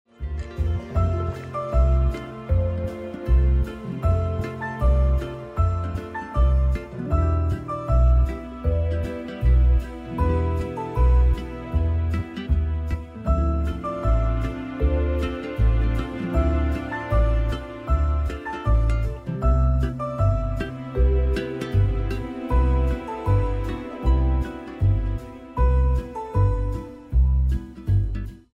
Маяковского настолько остро, навзрыд а главное - живо, не литературно, до меня никто не доносил, включая меня самого..